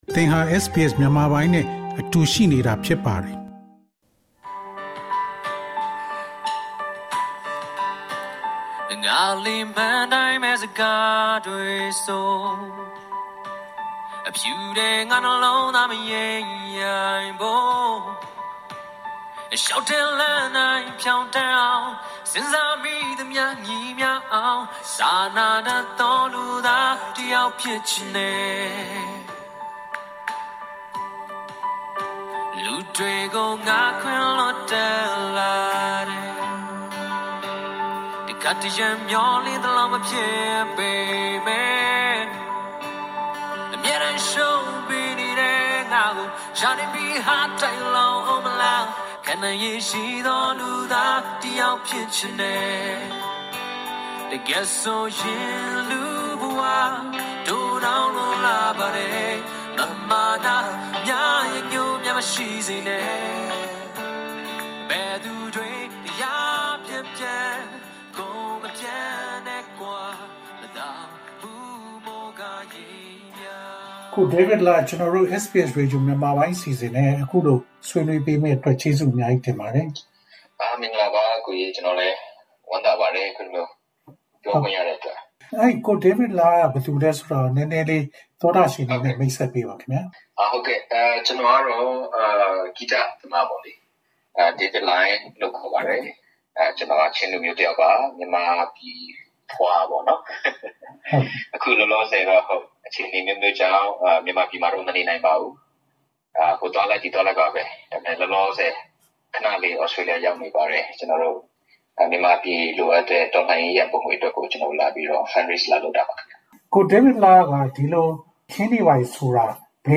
မေးမြန်းထားခန်း